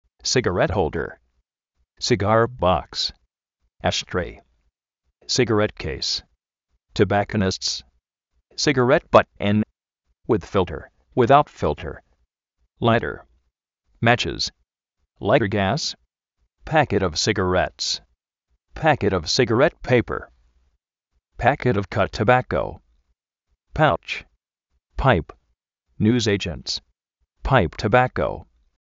sigarét jólder
sigár box
ásh-tréi
sigarét kéis
tobákounists
láiter
máchis
páip